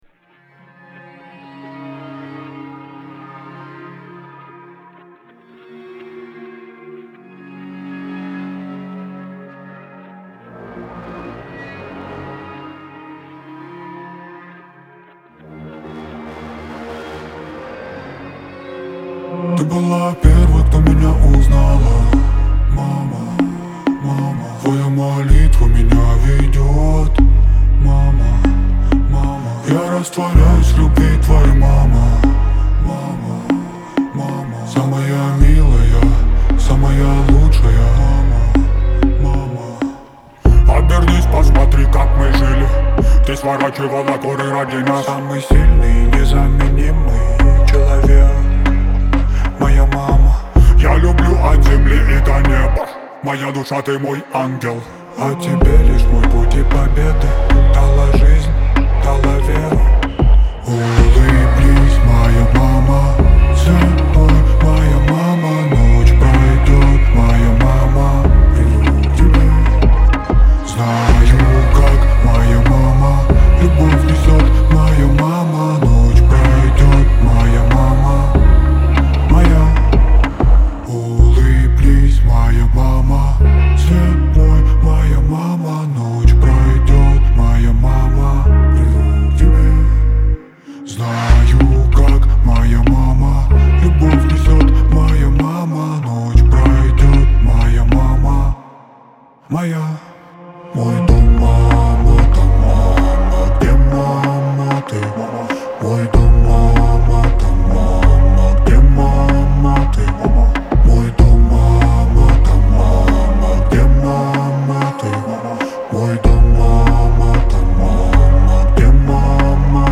pop , эстрада
ХАУС-РЭП